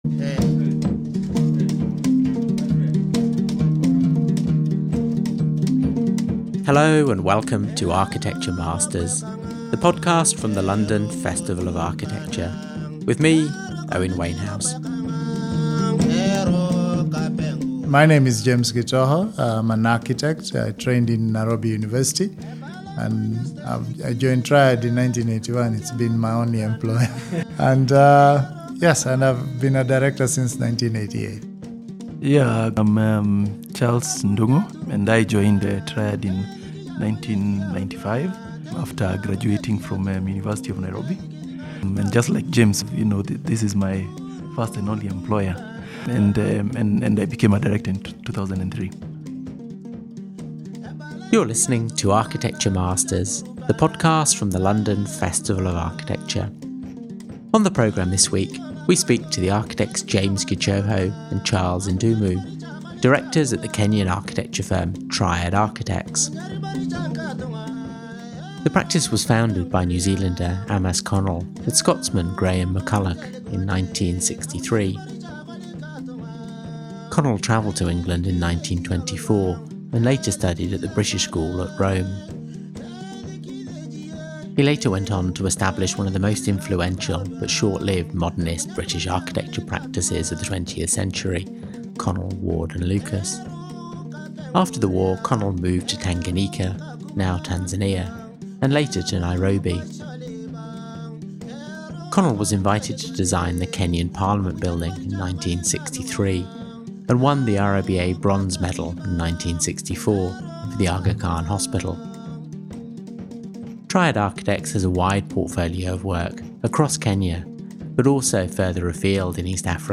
This episode was recorded in Nairobi as part of a series of episodes we’re bringing you from East Africa to explore identity and architecture.